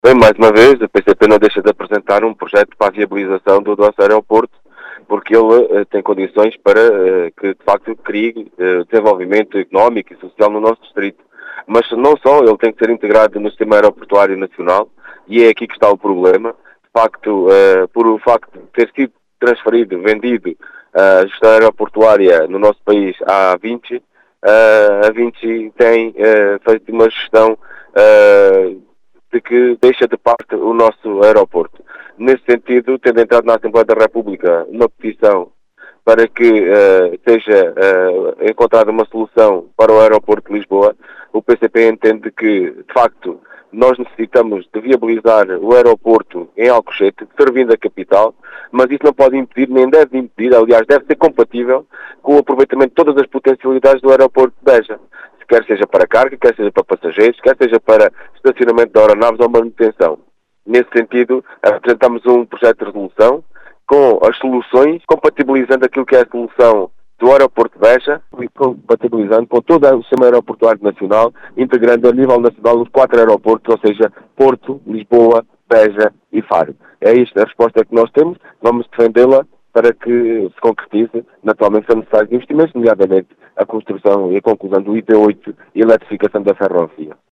As explicações são de João Dias, deputado do PCP eleito pelo distrito de Beja, que afirma que o aeroporto de Beja tem de “ser integrado no sistema aeroportuário nacional”.